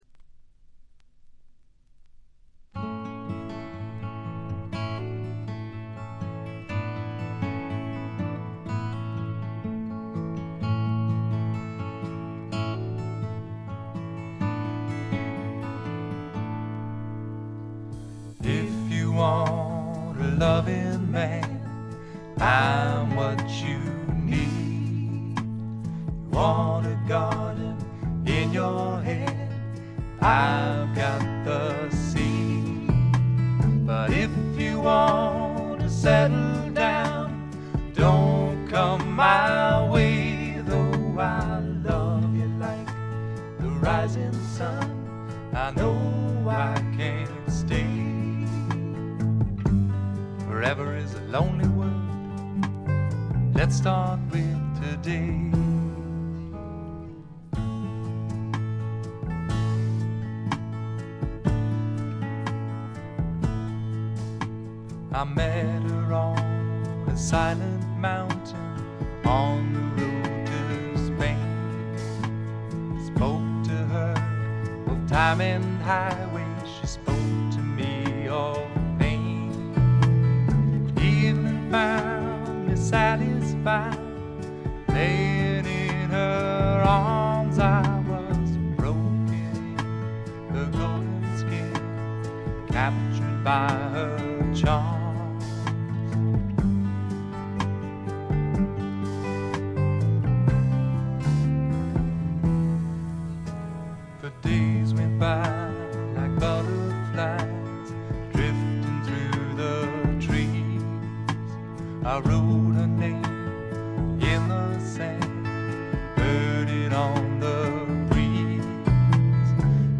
ごくわずかなノイズ感のみ。
シンプルなバックに支えられて、おだやかなヴォーカルと佳曲が並ぶ理想的なアルバム。
試聴曲は現品からの取り込み音源です。